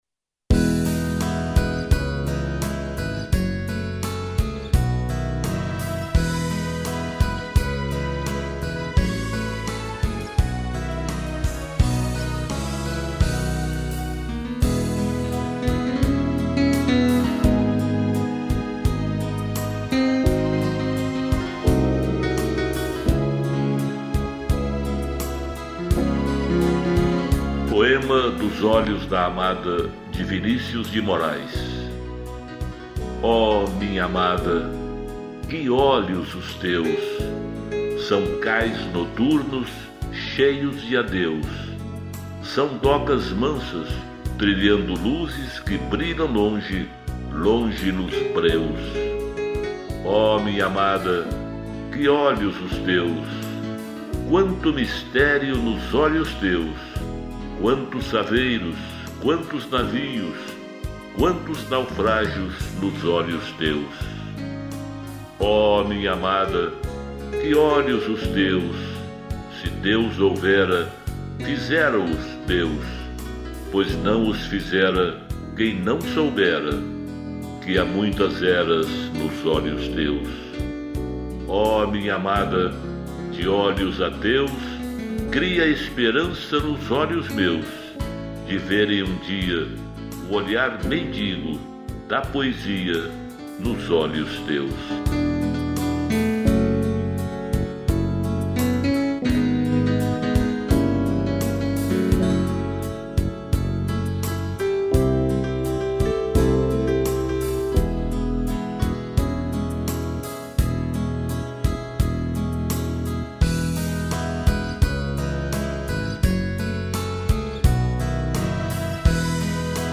Poemas de vários poetas interpretados